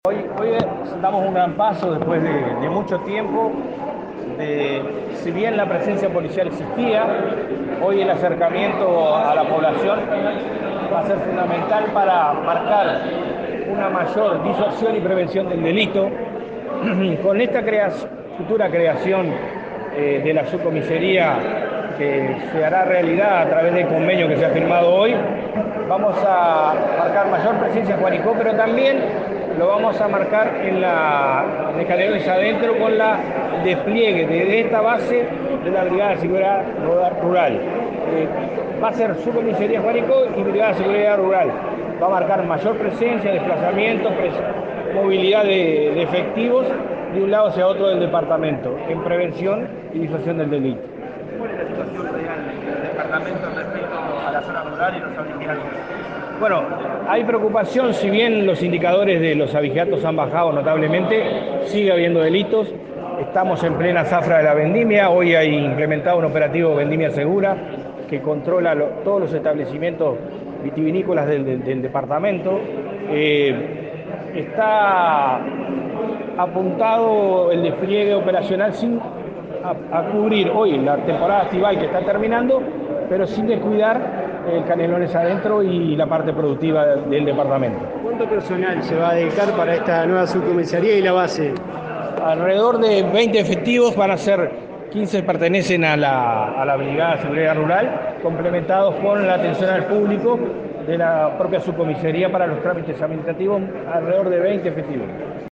Declaraciones a la prensa del jefe de Policía de Canelones, Víctor Trezza
El jefe de Policía de Canelones, Víctor Trezza, dialogó con la prensa acerca de la subcomisaría para Villa Joanicó, cuya obra fue lanzada este jueves